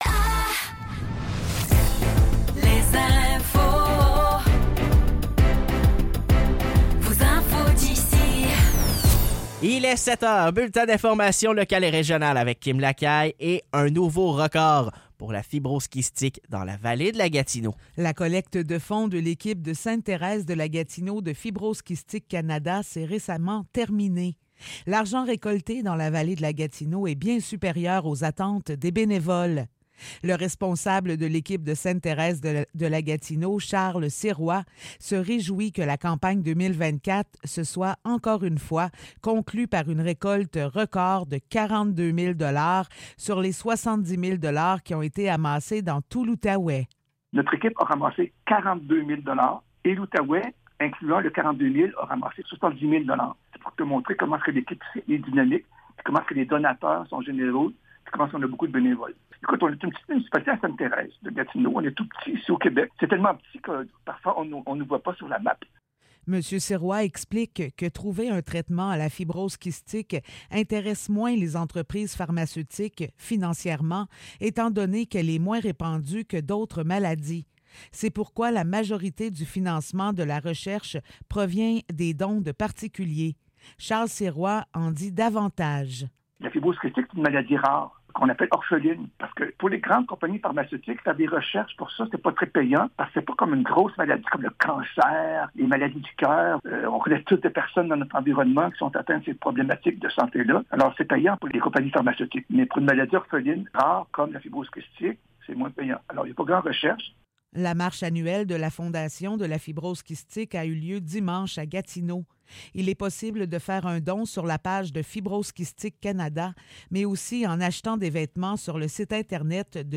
Nouvelles locales - 31 mai 2024 - 7 h